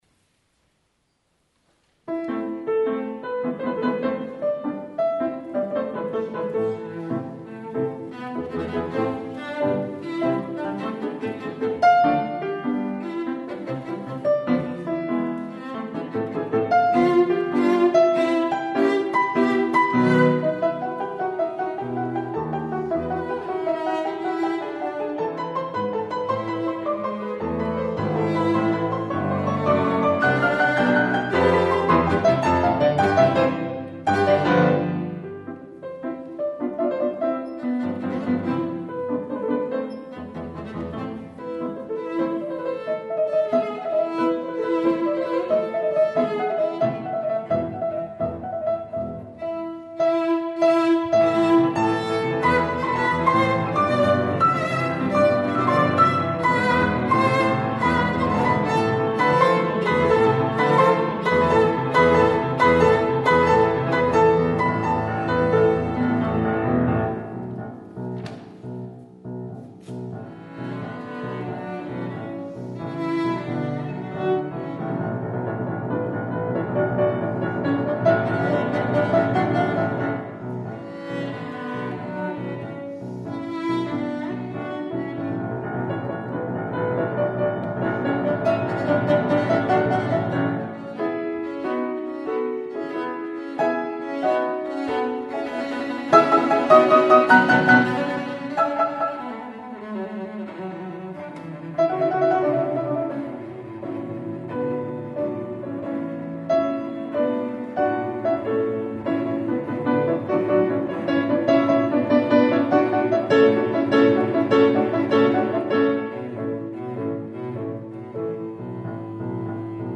cello
klaver